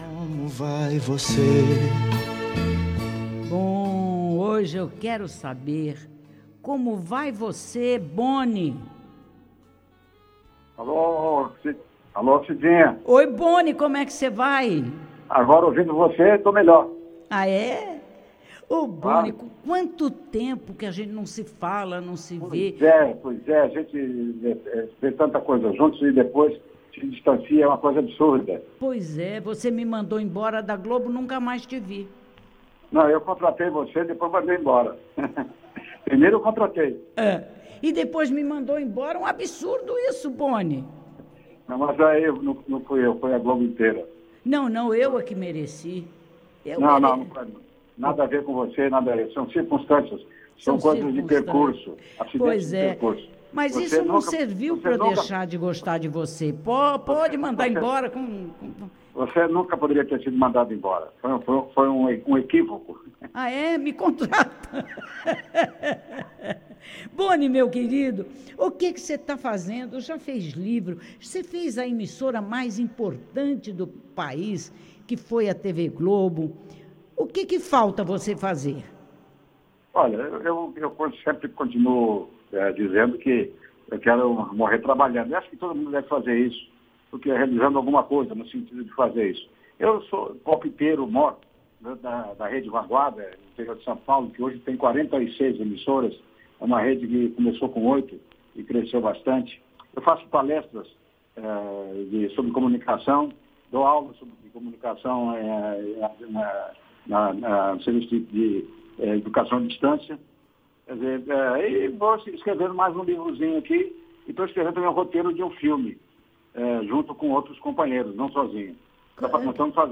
José Bonifácio de Oliveira Sobrinho, ou simplesmente Boni, foi o convidado do quadro “Como Vai Você”, do Programa “Cidinha Livre”, da Super Rádio Tupi, esta quarta-feira (02).